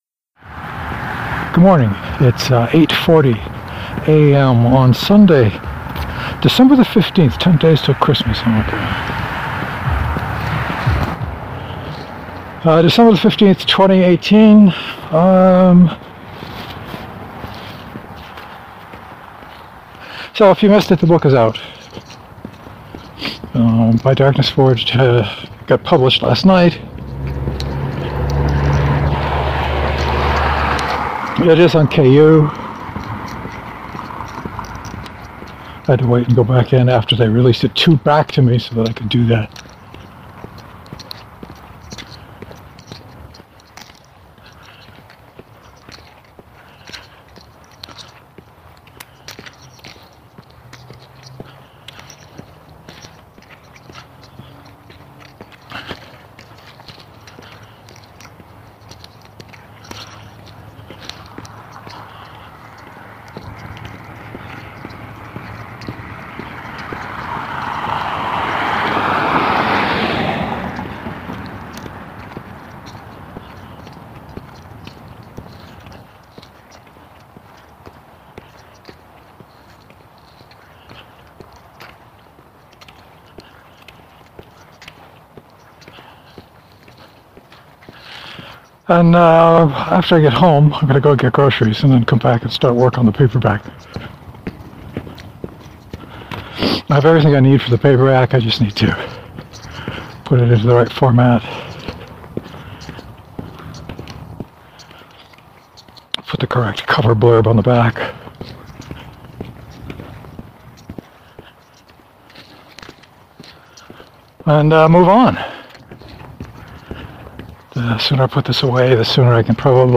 Lots of boots. I don’t remember walking more than talking but the wave form shows lots of walk and not much talk today.